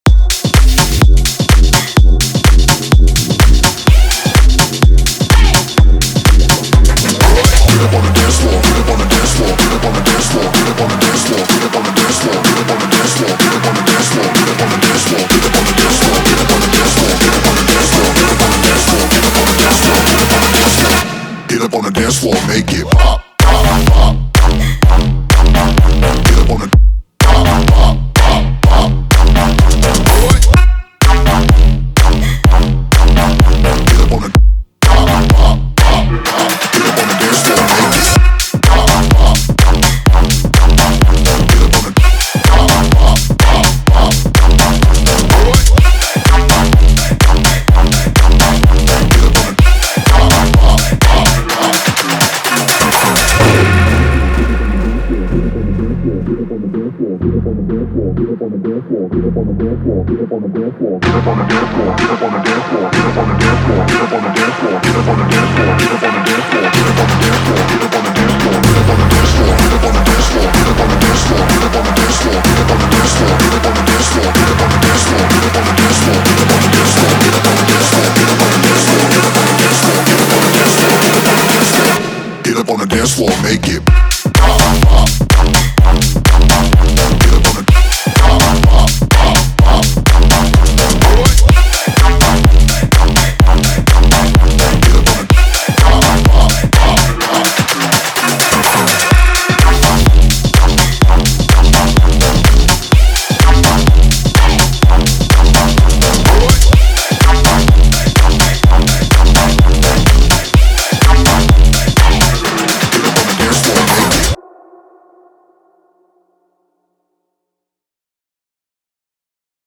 BPM126
MP3 QualityMusic Cut